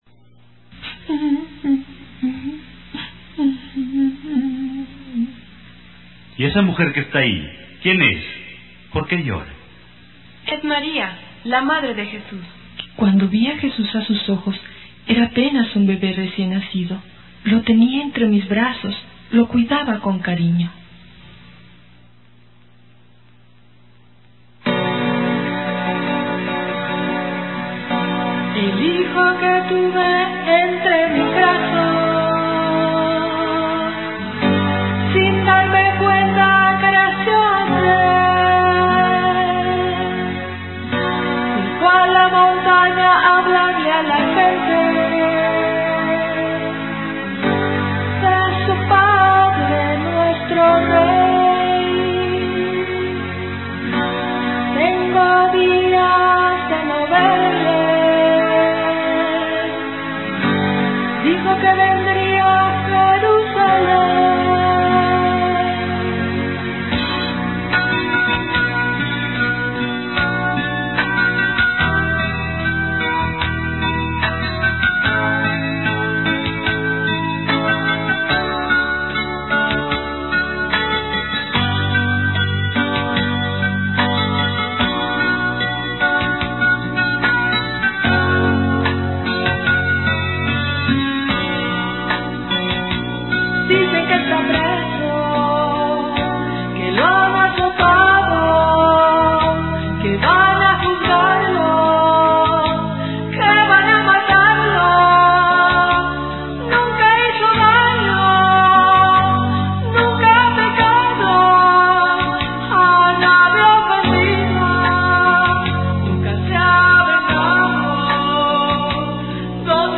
(Contralto)
(Tenor)